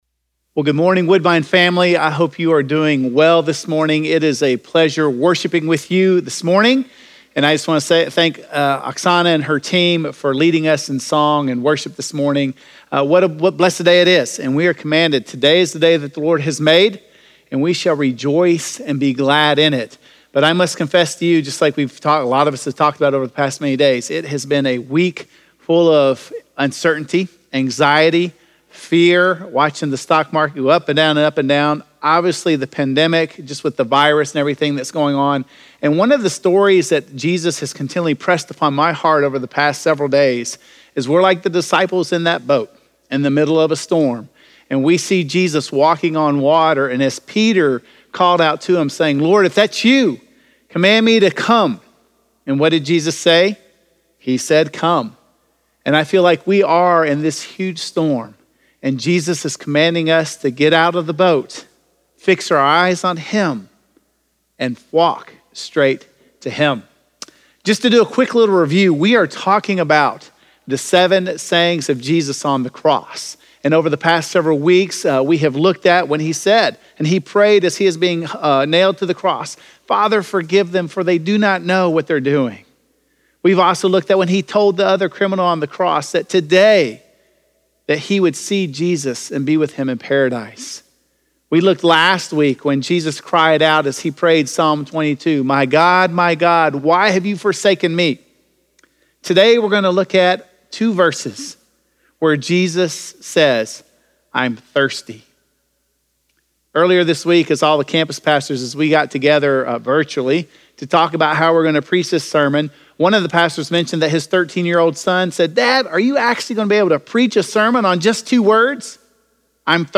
I Thirst - Sermon - Woodbine